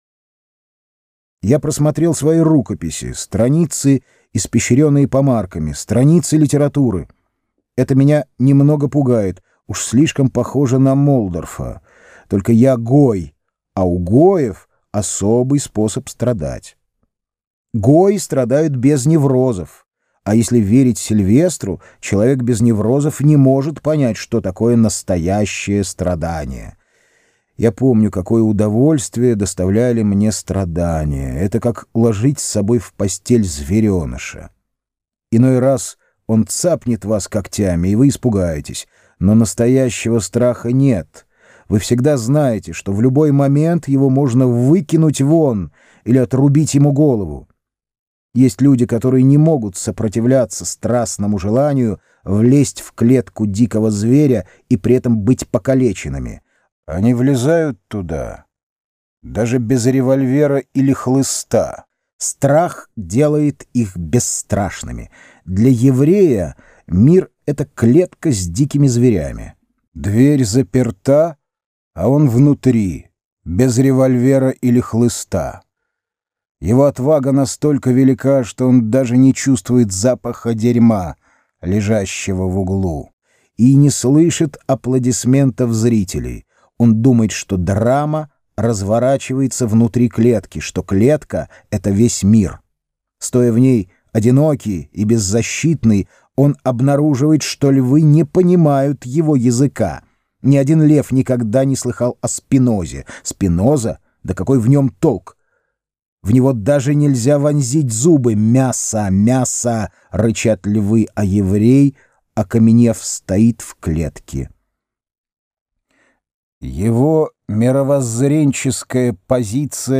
Аудиокнига: Тропик Рака